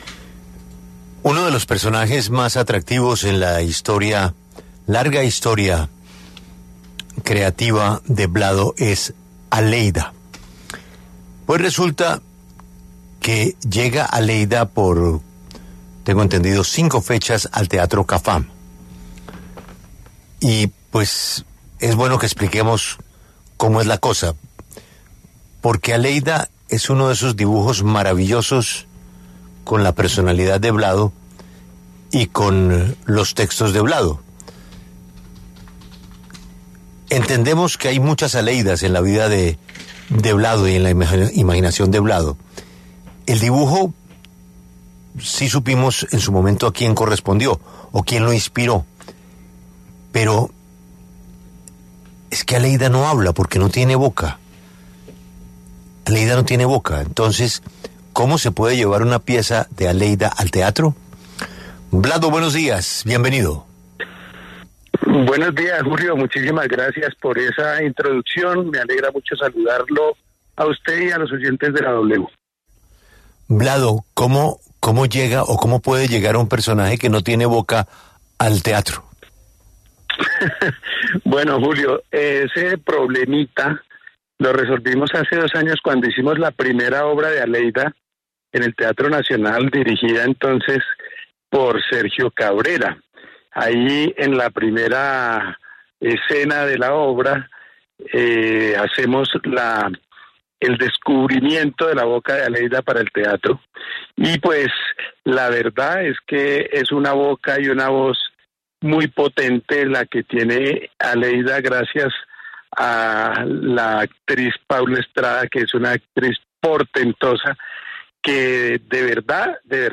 El caricaturista y periodista Vladimir Flórez habló en La W acerca de la puesta en escena de su personaje ‘Aleida’.